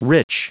Prononciation du mot rich en anglais (fichier audio)